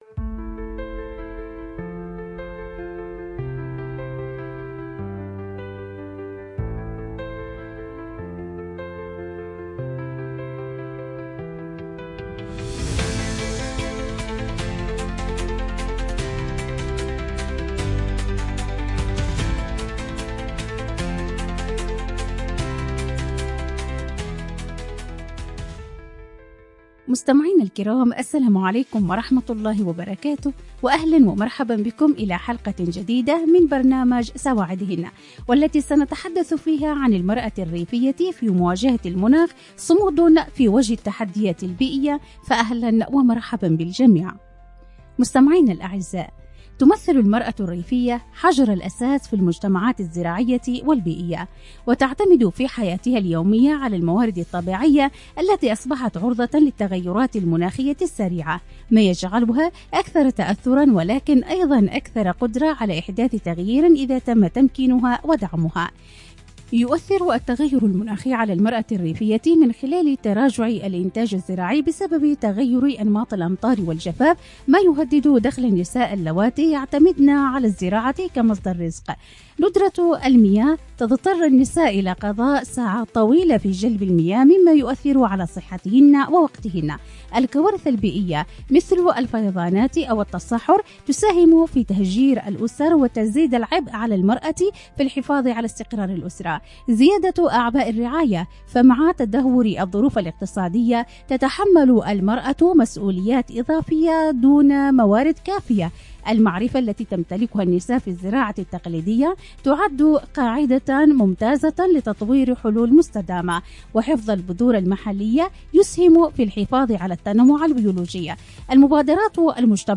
📻 المكان: عبر أثير إذاعة رمز